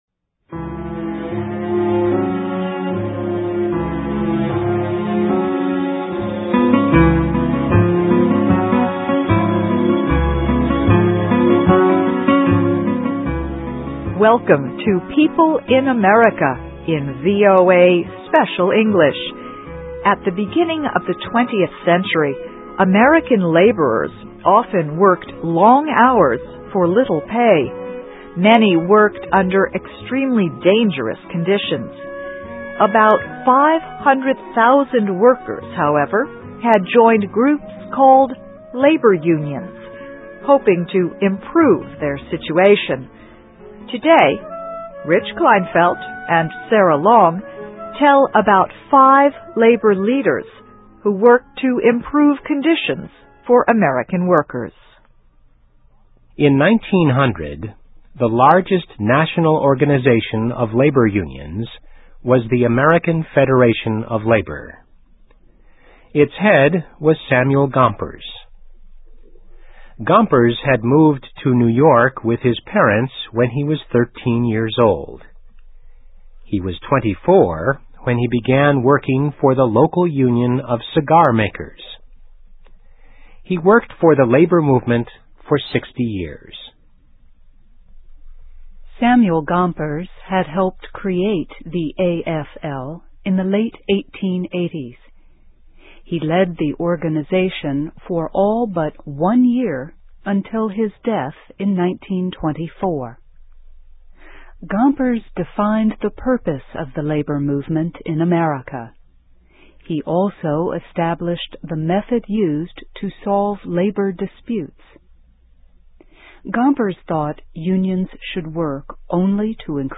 Biography - Five Labor Leaders Who Improved Conditions for American Workers (VOA Special English 2008-08-31)
Listen and Read Along - Text with Audio - For ESL Students - For Learning English